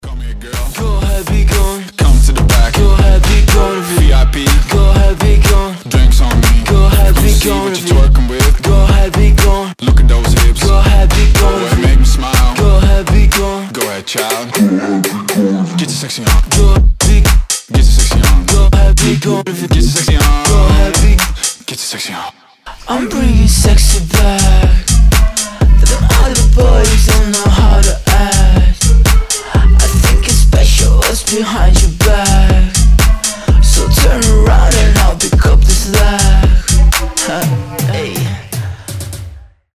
• Качество: 320, Stereo
deep house
чувственные
Tech House